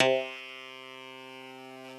genesis_bass_036.wav